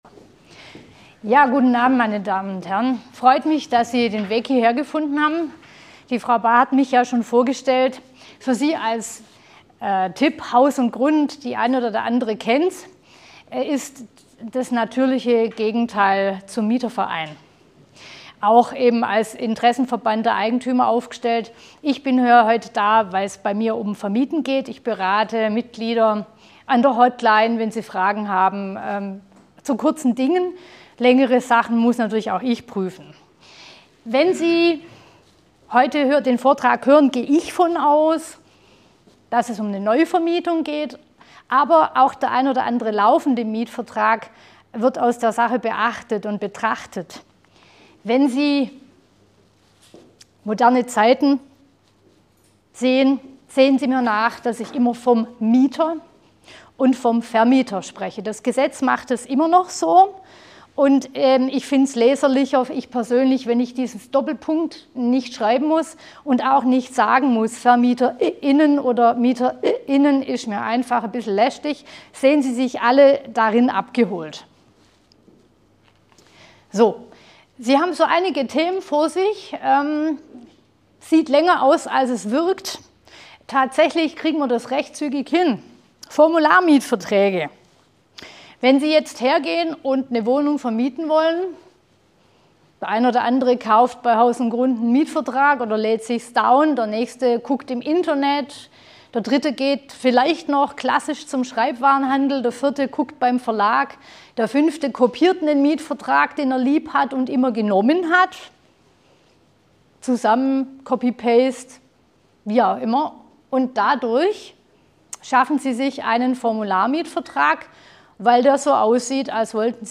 Selbstwenn das Mietvertragsformular rechtssichere Formulierungenenthält, gibt es immer noch genügend Fallstricke undFehlerquellen beim Ausfüllen. Der Vortrag soll Ihnen bei einemguten Start ins Mietverhältnis helfen.